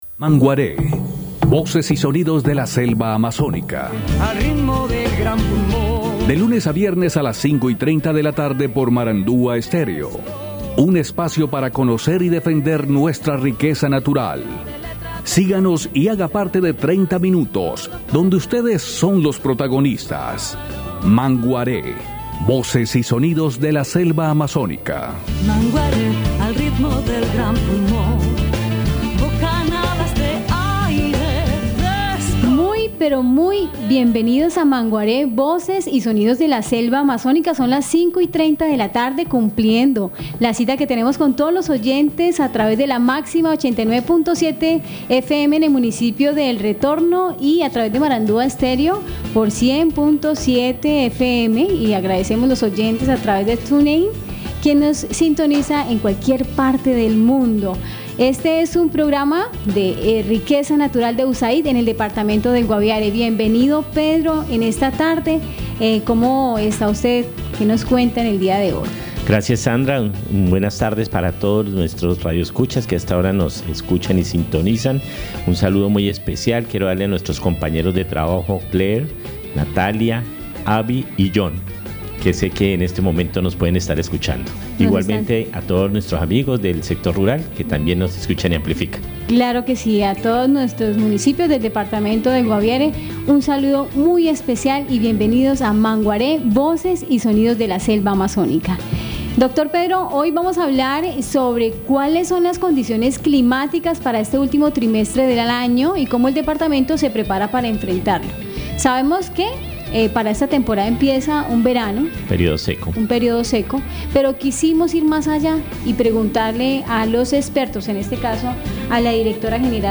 Cómo se prepara el Guaviare frente a las condiciones climáticas en este último trimestre del año, José Jacinto Cubides Secretario de Agricultura del departamento, responde.